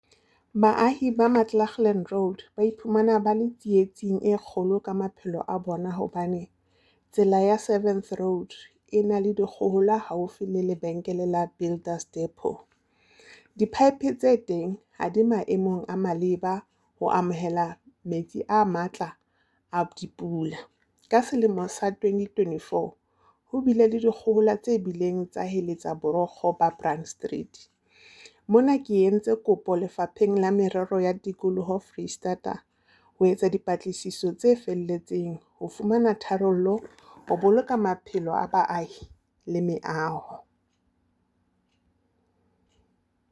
Sesotho soundbite by Cllr Palesa Mpele.